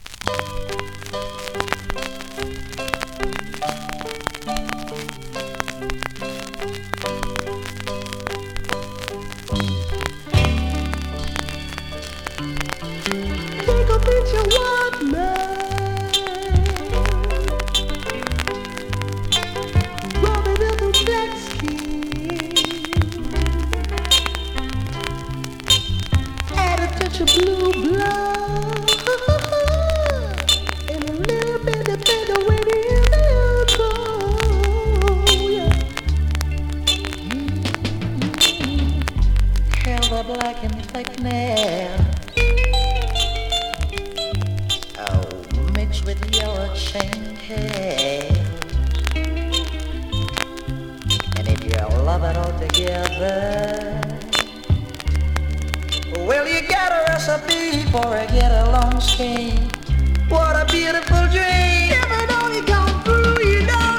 スリキズ、ノイズそこそこあります。